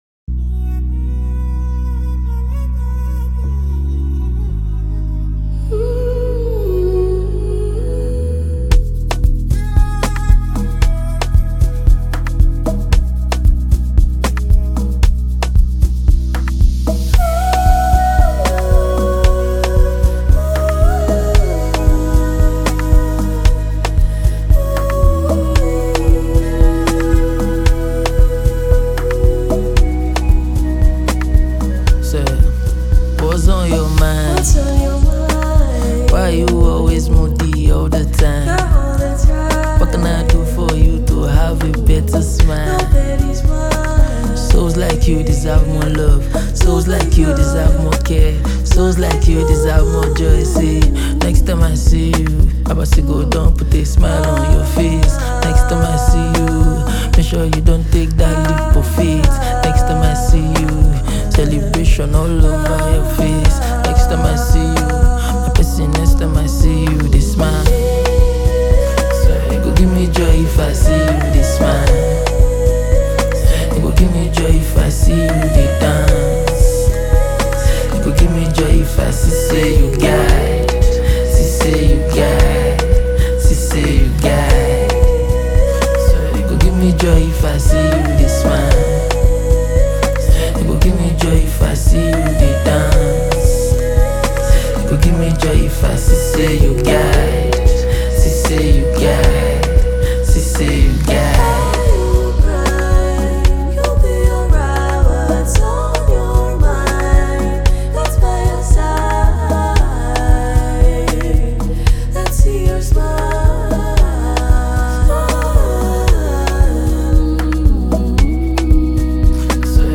soul-lifting tune